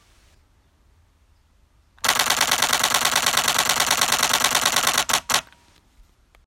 Nikkon D850 mit Batteriegriff MB-D18 – 9/s Bilder (XQD Speicherkarte)
D850-mit-Gripp.m4a